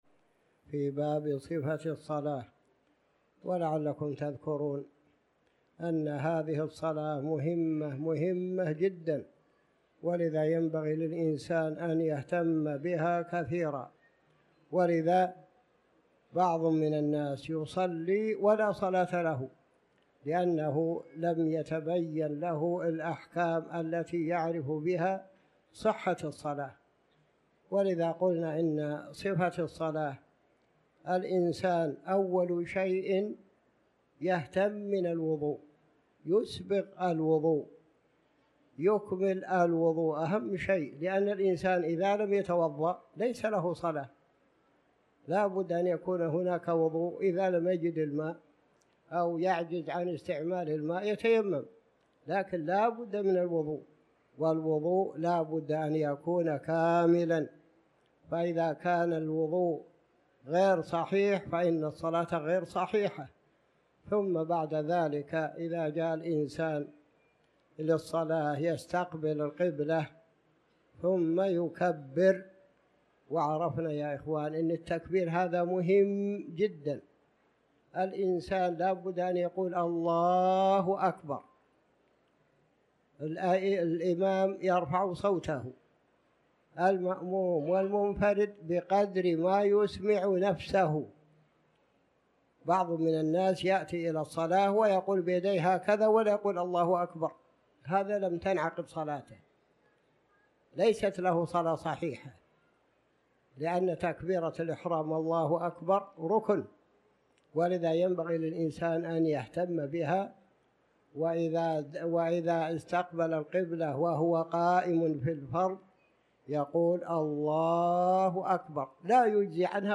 تاريخ النشر ٣ ذو القعدة ١٤٤٠ هـ المكان: المسجد الحرام الشيخ